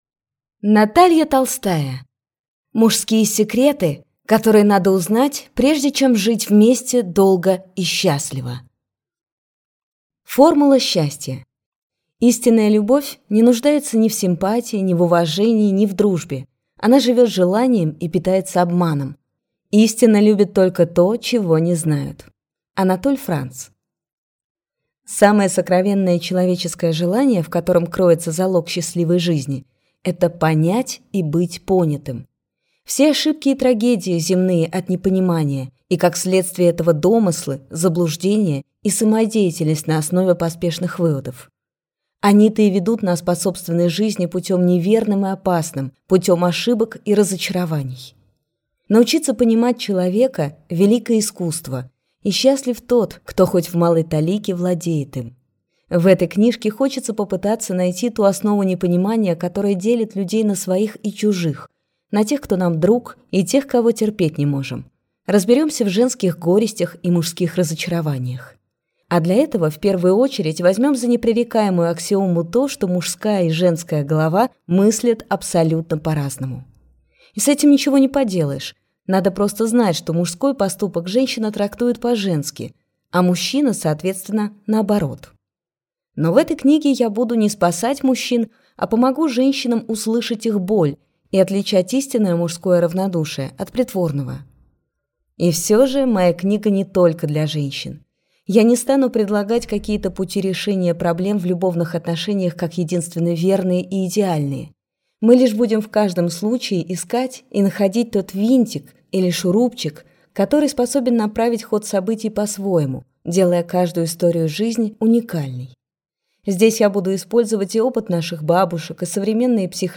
Аудиокнига Мужские секреты, которые надо узнать, прежде чем жить вместе долго и счастливо | Библиотека аудиокниг